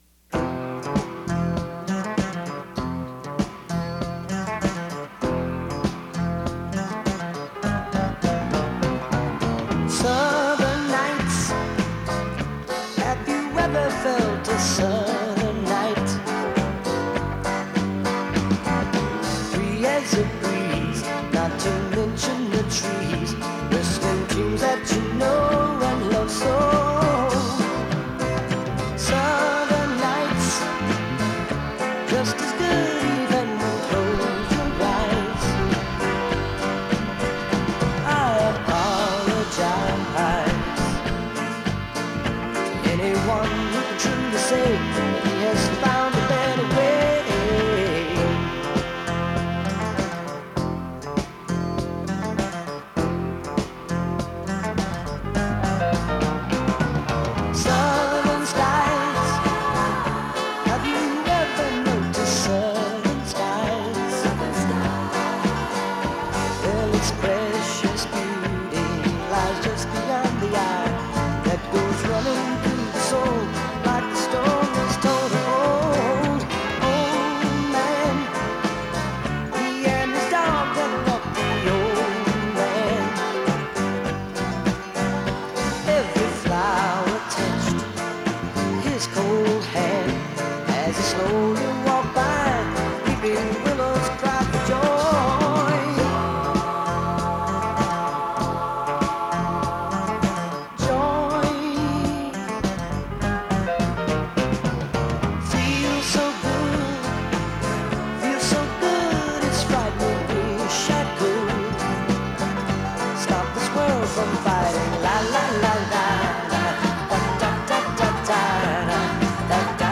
This is one very long 8-track tape.